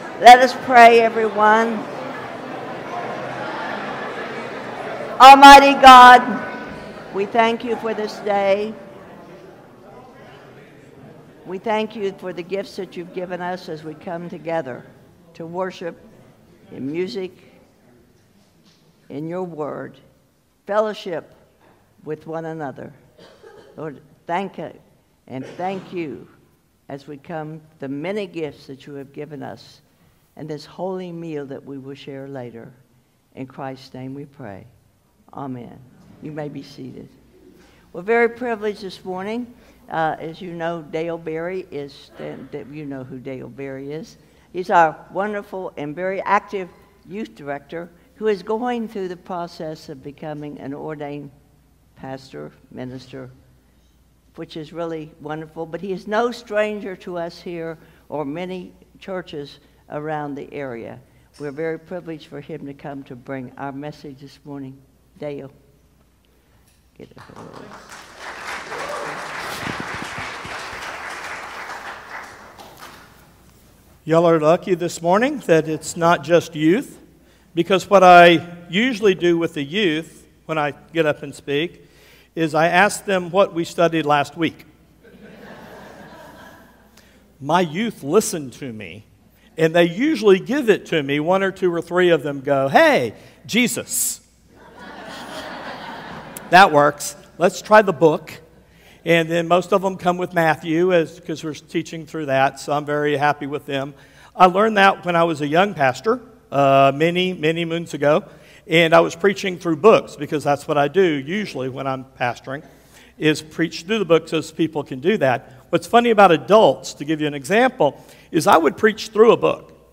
Sermons | Asbury Methodist Church
Guest Speaker